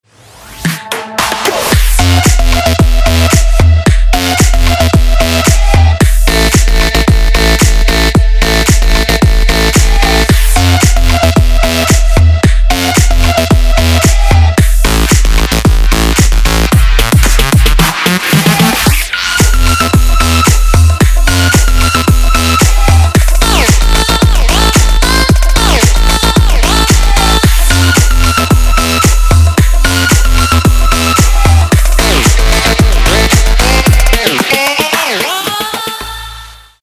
• Качество: 224, Stereo
бит
Подвижный электронный рингтон